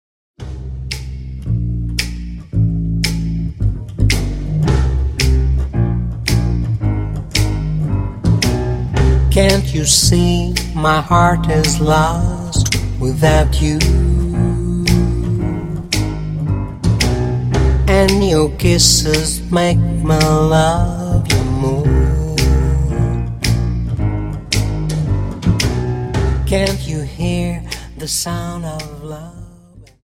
Dance: Slowfox 28 Song